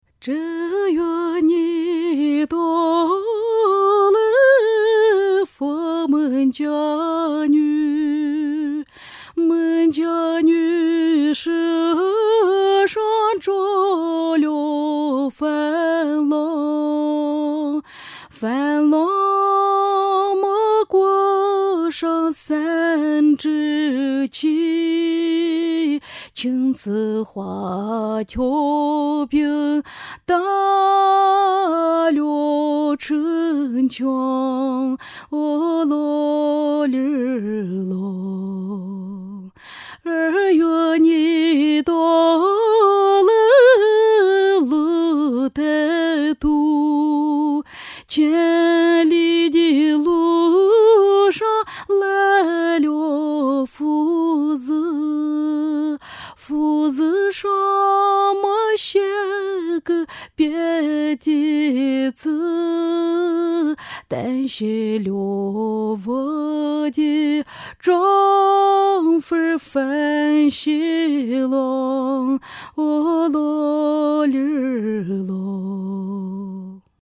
Dungan singer
is singing Dungan song - Bishkek